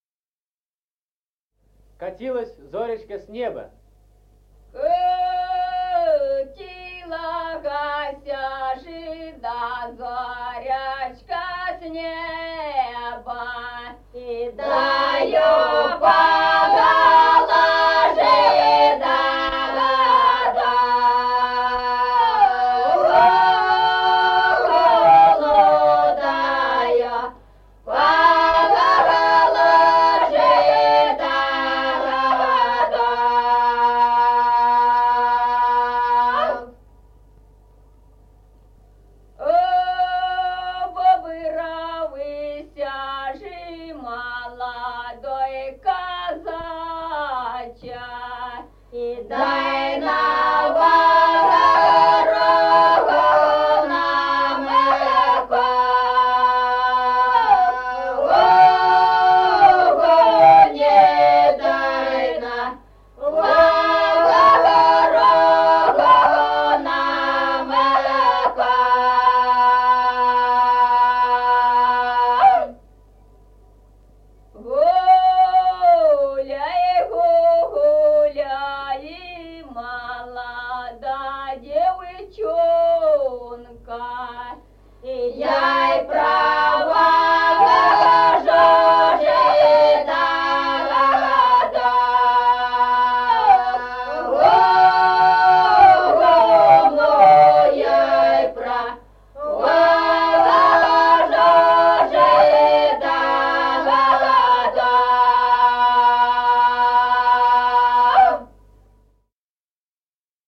Музыкальный фольклор села Мишковка «Катилася ж да зоречка», лирическая.